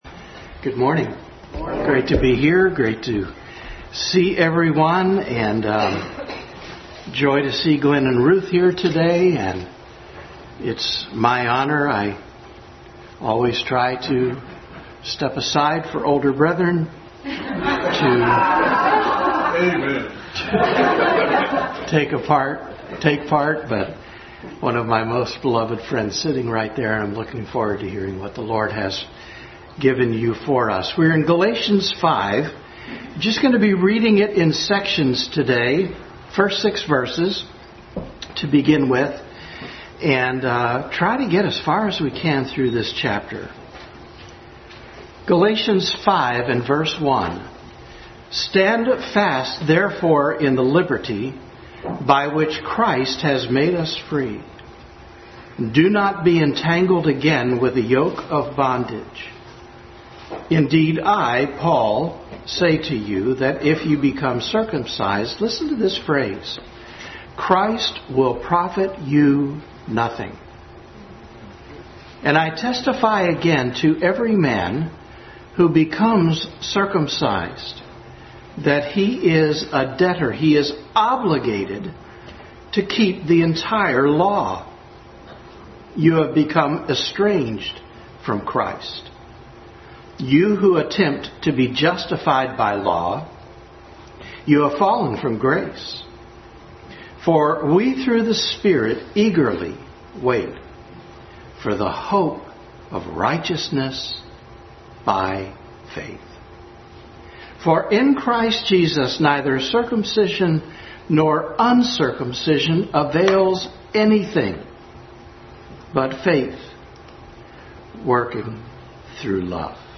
Adult Sunday School Class continued study in Galatians.